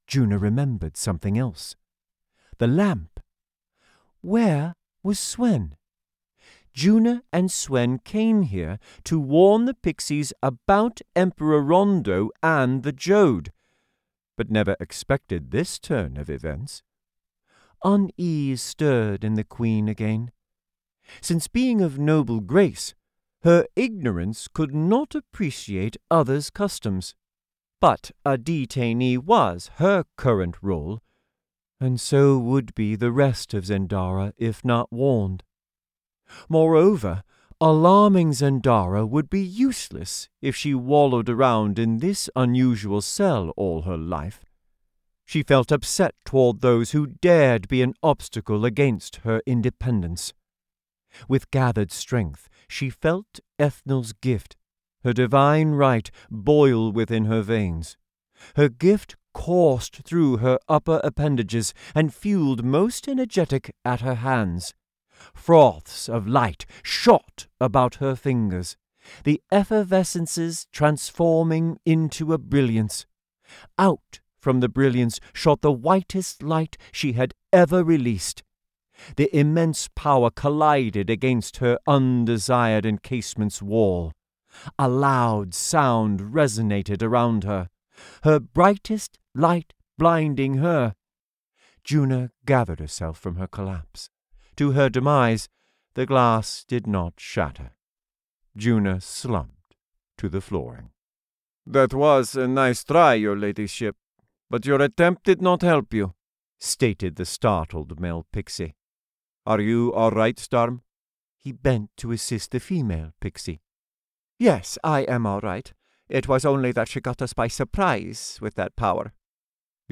Audiobook Sample Winner: